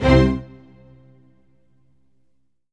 ORCHHIT C3-L.wav